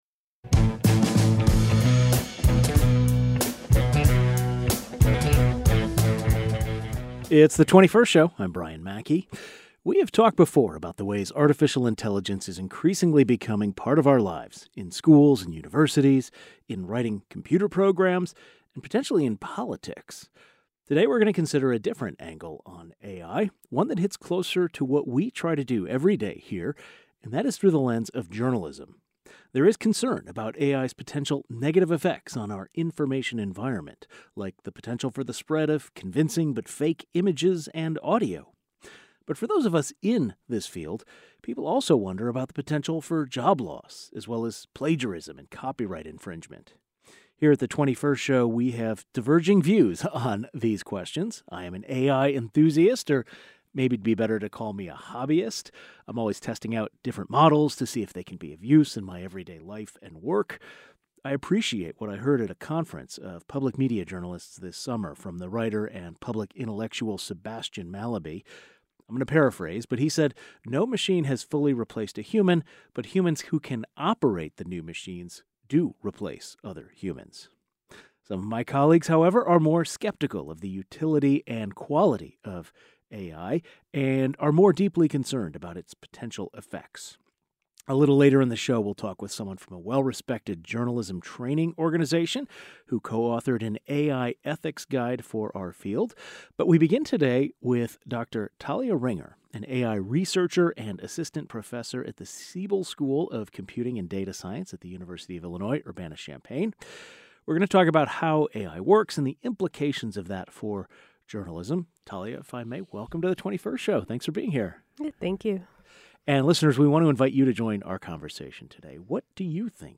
An AI researcher and a Journalist who co-authored an AI ethics guide for people who work in newsgathering join the program.